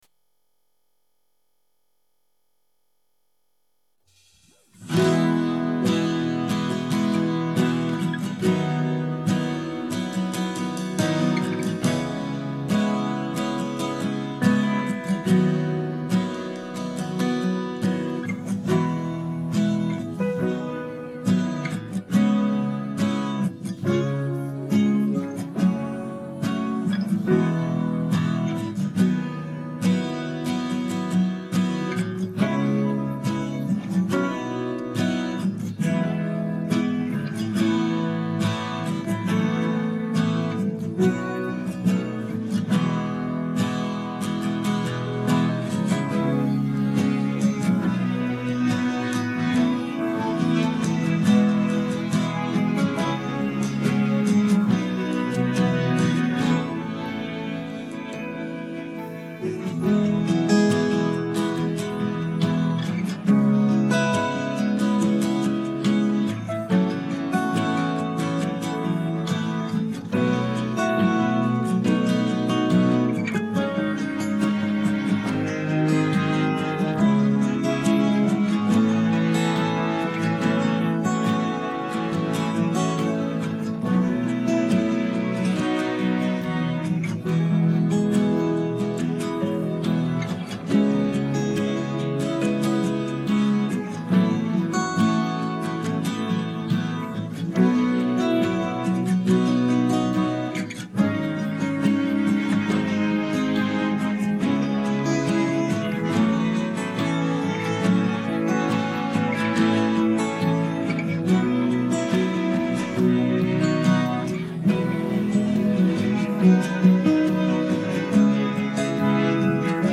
Inspiring guitar